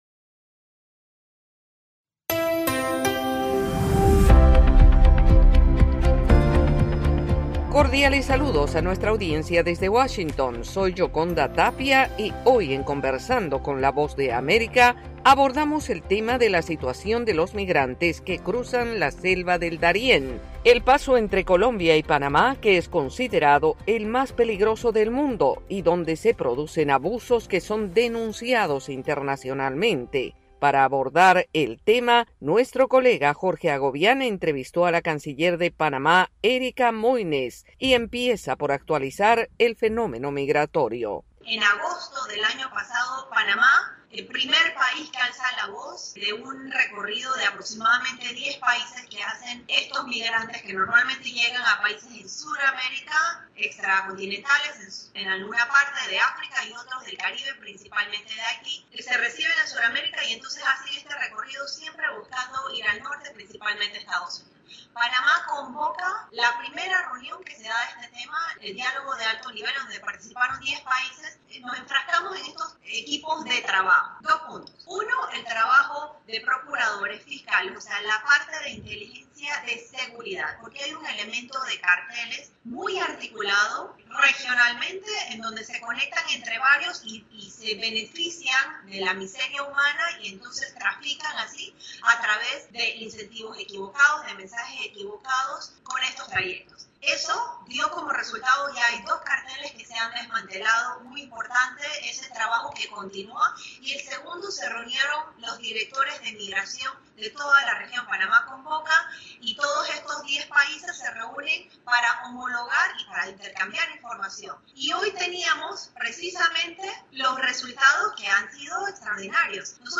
Conversamos con la canciller de Panamá, Erika Mouynes, evaluando la situación de los migrantes que pasan por la selva del Darién para llegar a territorio panameño.